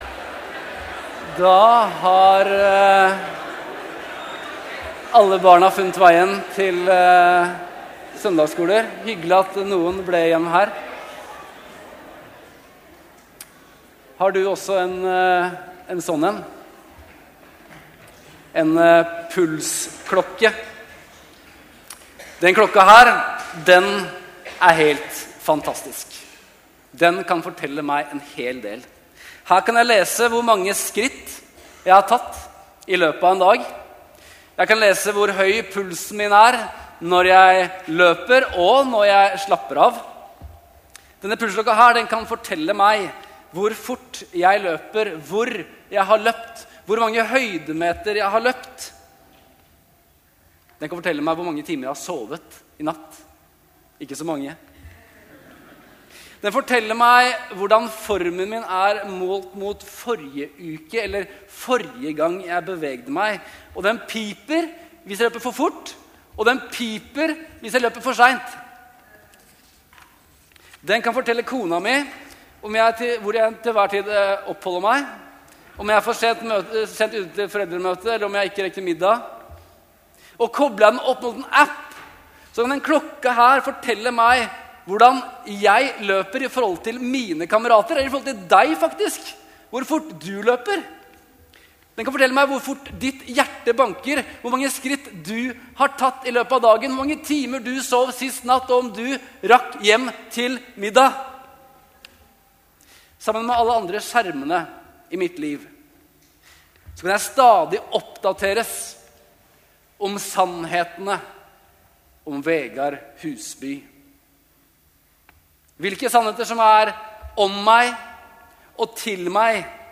Morgen gudstjenesten 31. oktober - Livet i høstmørke | Storsalen